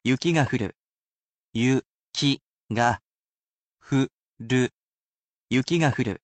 This may show you grammar, writing, and reading, as I actually read these aloud for you, as well.